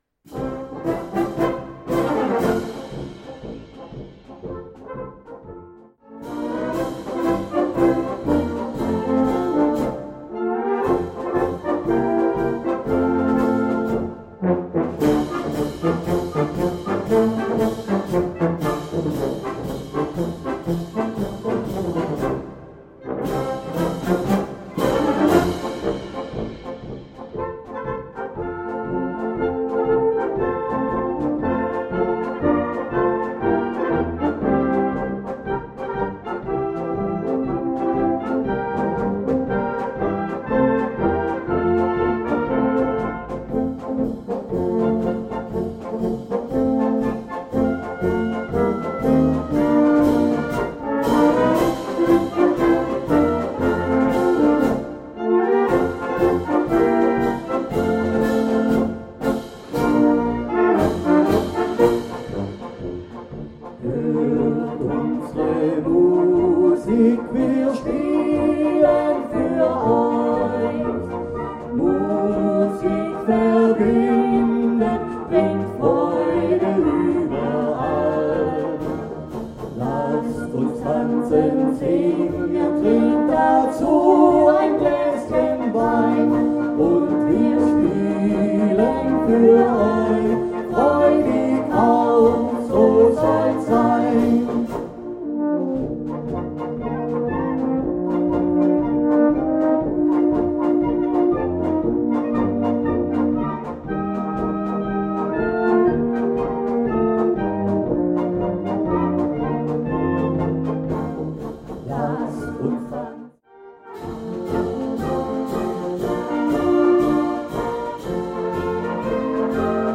Polka Diese spritzige Polka ist leicht und beschwingt.